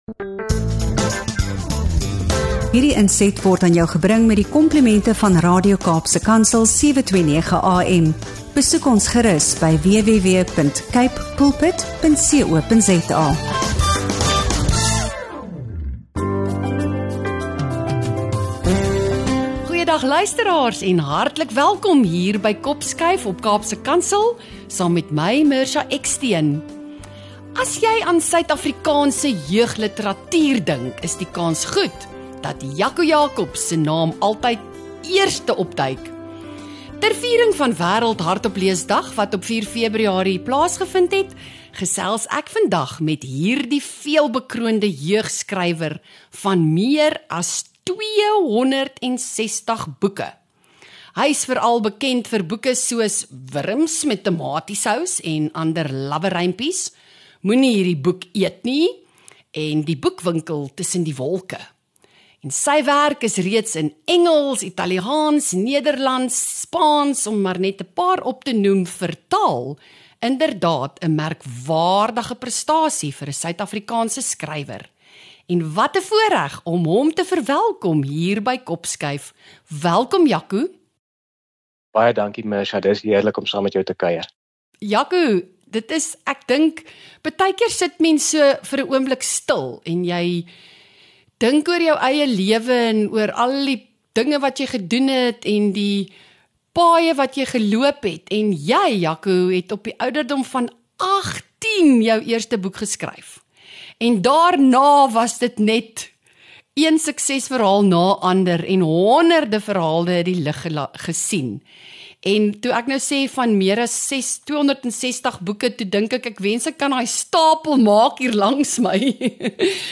Vier Wêreld-hardopleesdag 2026 saam met veelbekroonde jeugskrywer Jaco Jacobs op die Kopskuif-podcast. Luister hoe die skrywer van meer as 260 boeke van sy gewilde rympies voorlees en gesels oor lees, jeugliteratuur en die wonderlike wêreld van boeke in Suid-Afrika.